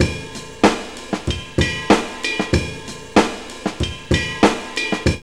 JAZZLP2 95.wav